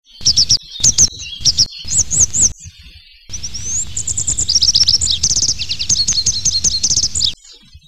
Bergeronnette des ruisseaux, motacilla cinerea